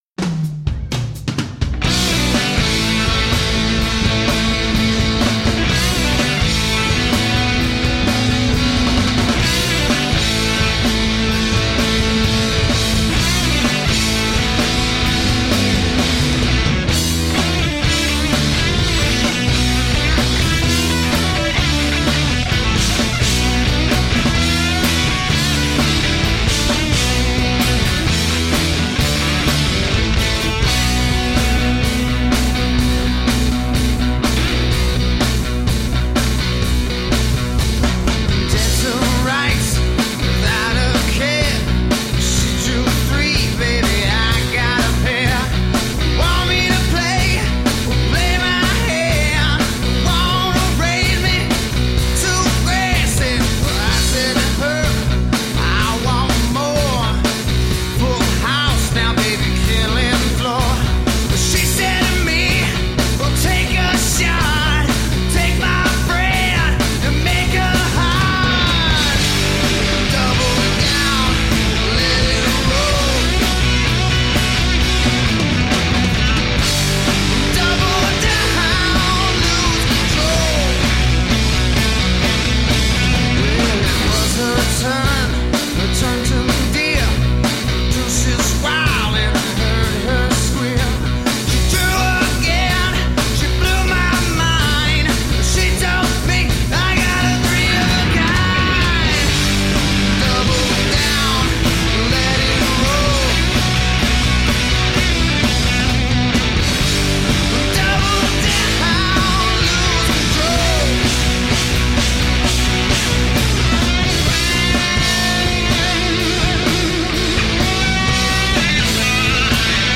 Original, blues-based rock and roll.
Tagged as: Hard Rock, Metal